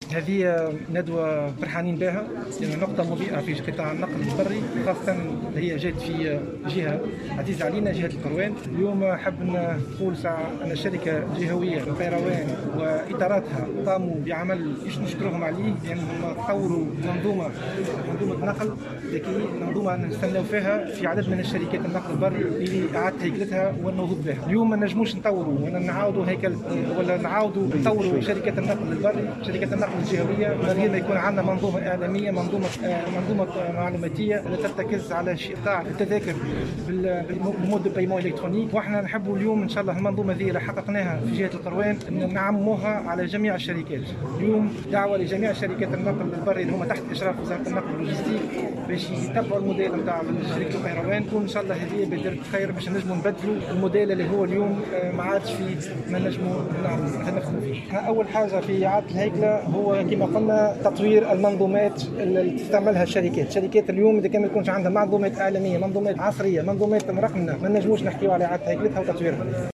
و أضاف الوزير في تصريح لمراسل الجوهرة "اف ام" ,أنه لا يمكن تطوير شركات النقل البري الجهوية دون منظومة معلوماتية مرقمنة ,ترتكز على إقتطاع التذاكر و الخلاص الإلكتروني ,مشددا على ضرورة تعميم التجربة في بقية شركات النقل بكافة الولايات.